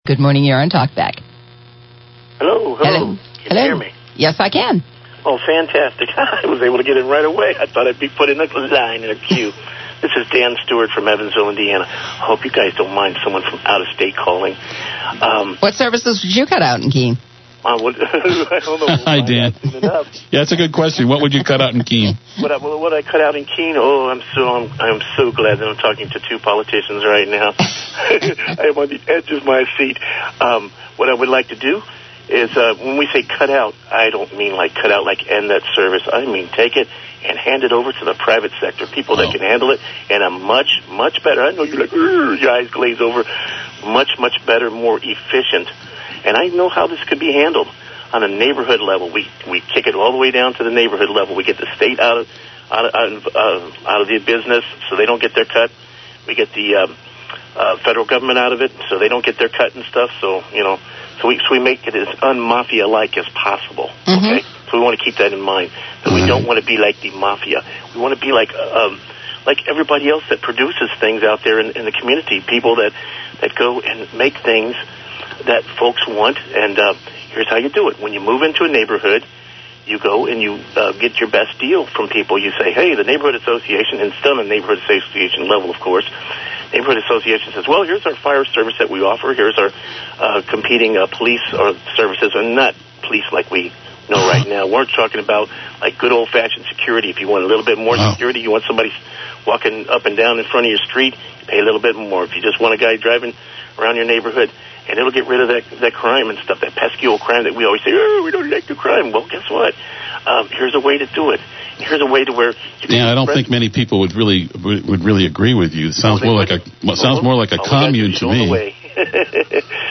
Liberty activists called WKBK’s Talkback this morning and discussed free market services, courtroom oppression, and running red lights.
You’ll also hear the smarmy statist guy call in to try to show everyone how smart he is.